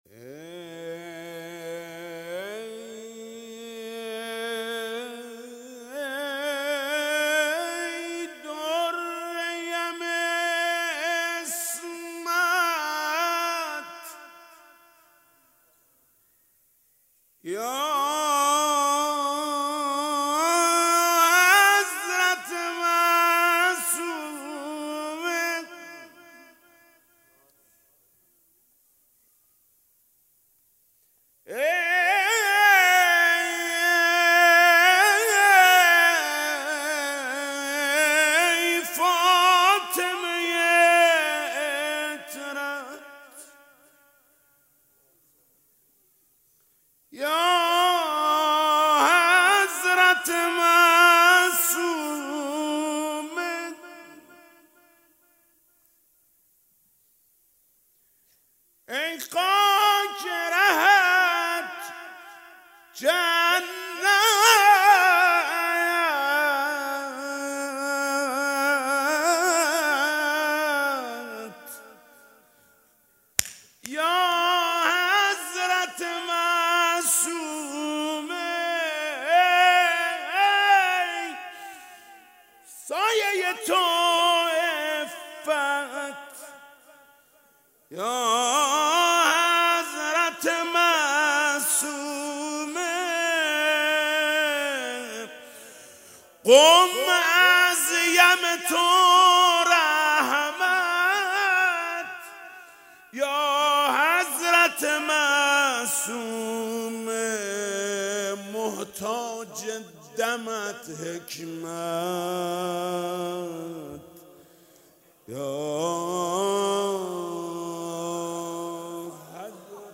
مداحی وفات حضرت‌ معصومه (س) با صدای محمود کریمی + صوت
تهران- الکوثر: مداحی و سینه‌زنی دم آخر بی تو چشم من گریونه را با صدای محمود کریمی به مناسبت وفات حضرت فاطمه معصومه (س) می‌شنوید.